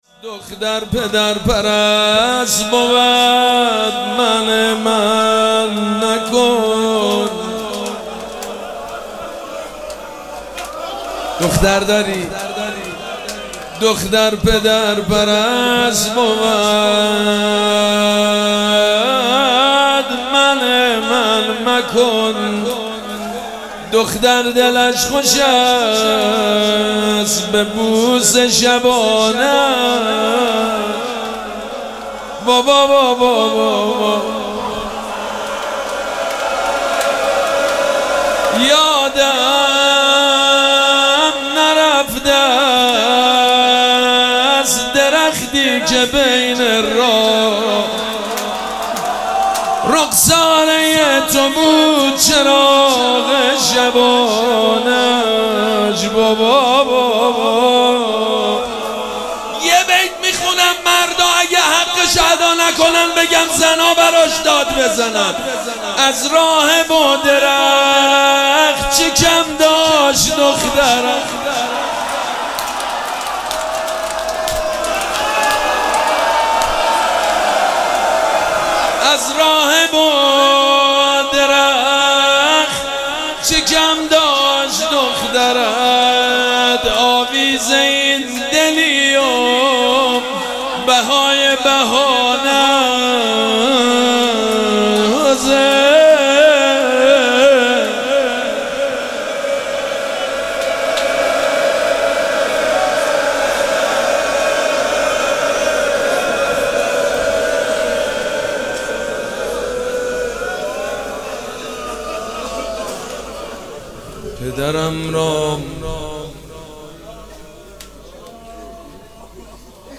مراسم عزاداری شب سوم محرم الحرام ۱۴۴۷
روضه
مداح